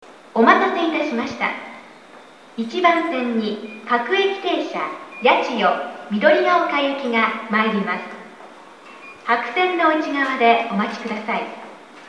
駅放送